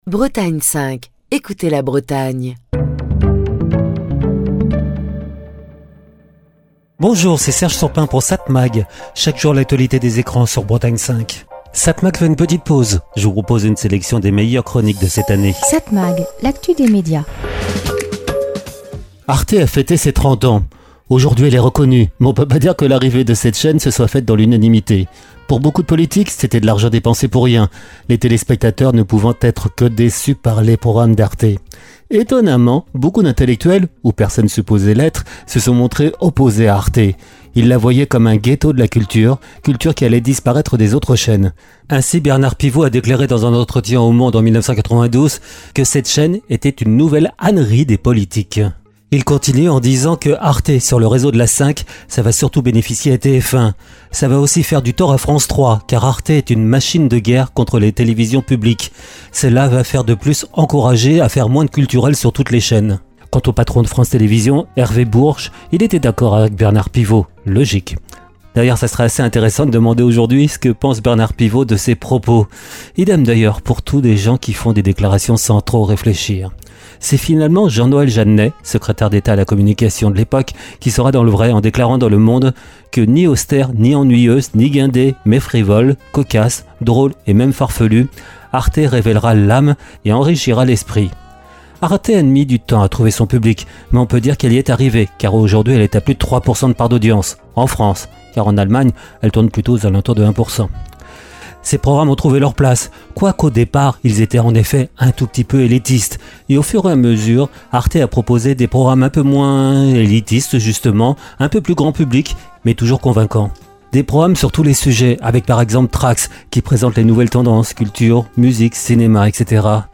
Chronique du 15 juillet 2025.